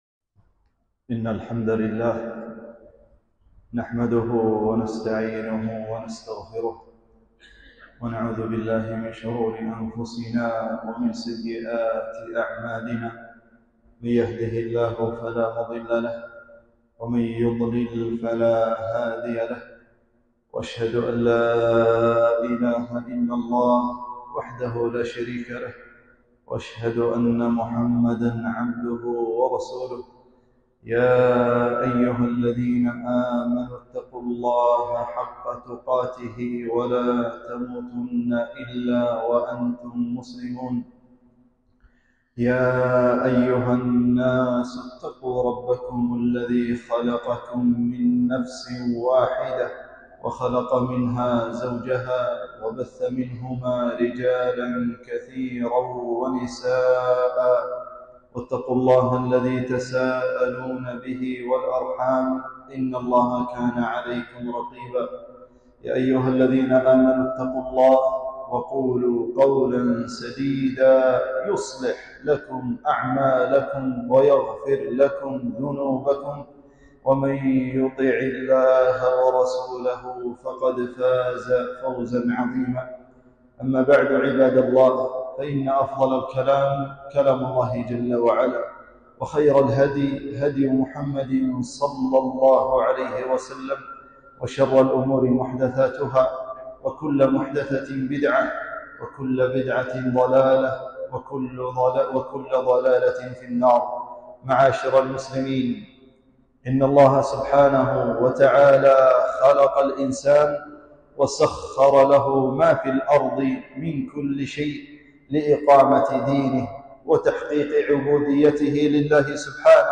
خطبة - خطر المخدرات والمسكرات